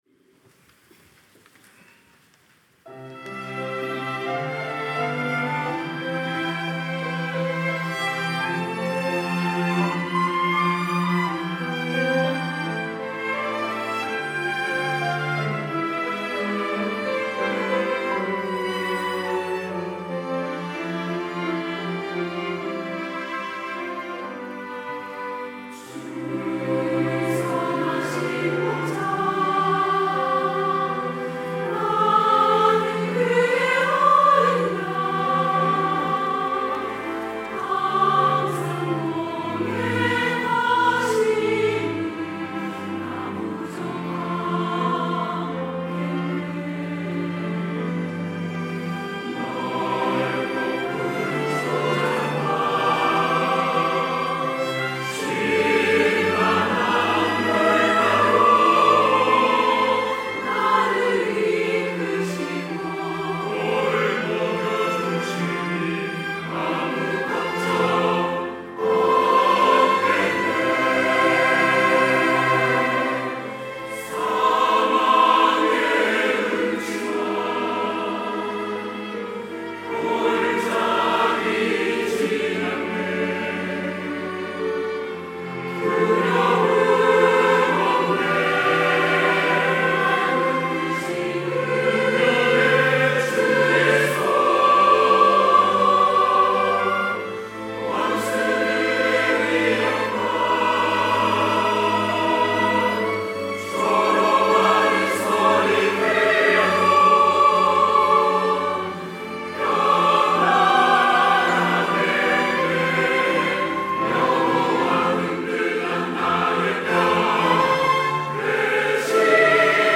호산나(주일3부) - 주는 선하신 목자
찬양대